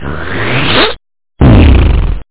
SlurpPfff!.mp3